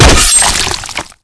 zombie_attack_2.wav